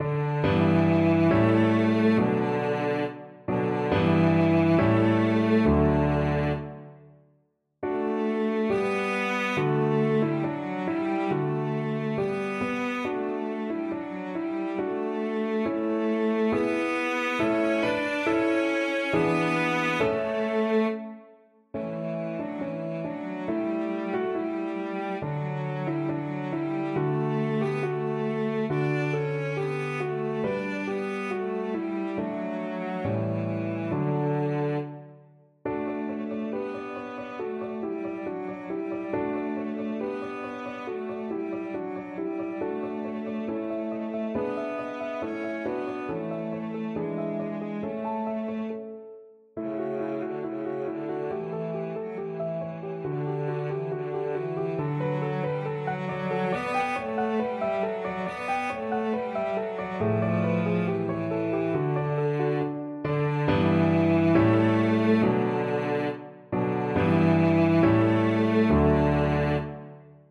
Cello version
Andantino = c.69 (View more music marked Andantino)
2/4 (View more 2/4 Music)
Cello  (View more Intermediate Cello Music)